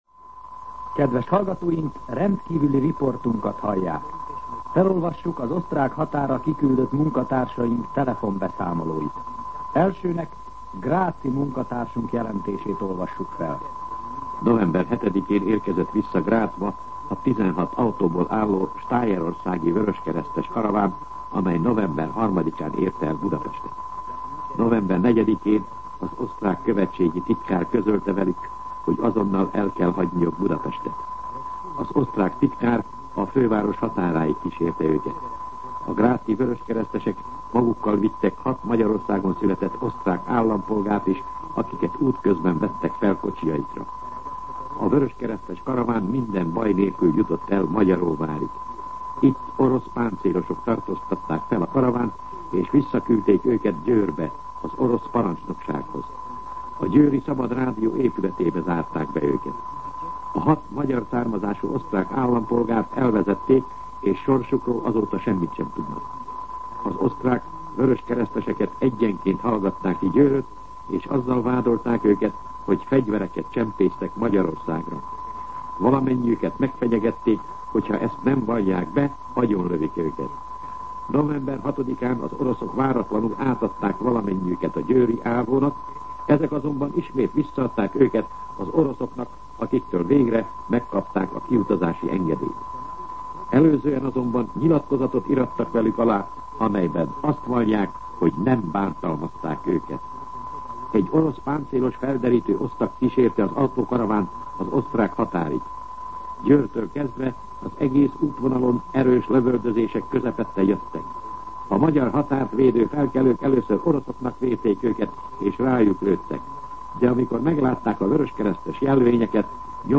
MűsorkategóriaTudósítás